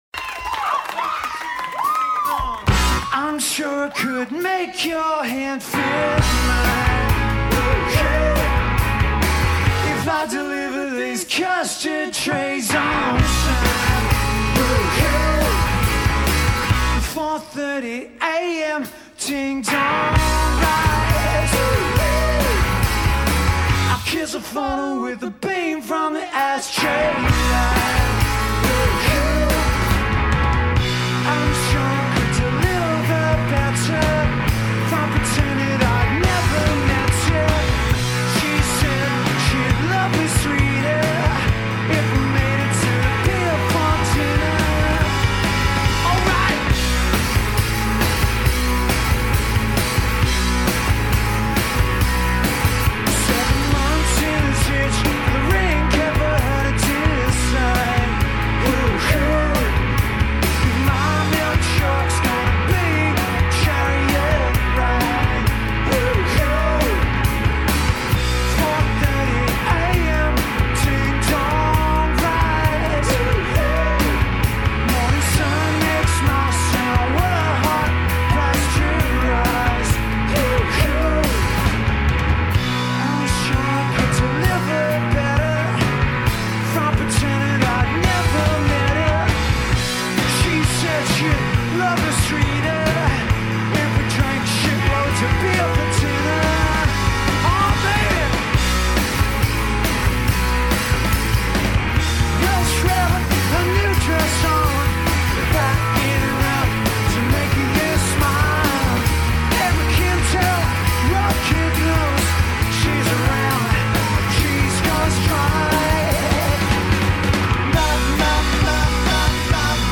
Recorded at The Enmore Theatre, Sydney
live at The Enmore Theatre
power pop band